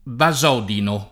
[ ba @0 dino ]